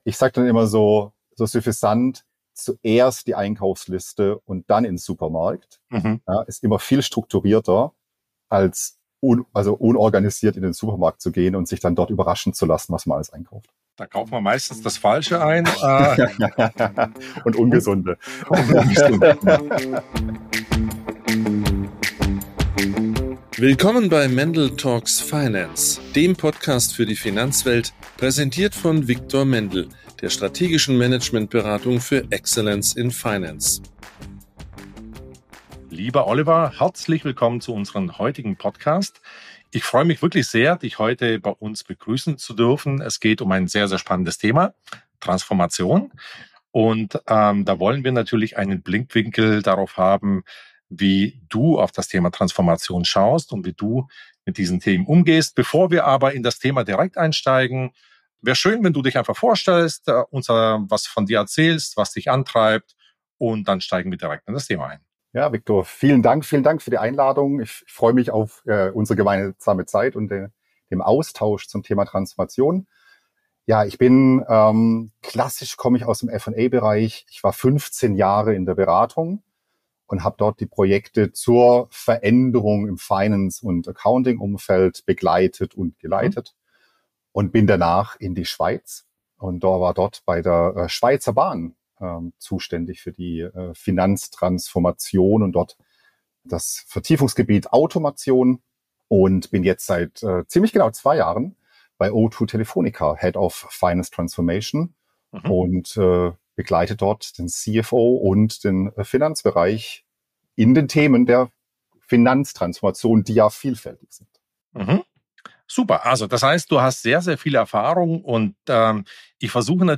Es war ein Gespräch, aus dem jeder was mitnehmen kann!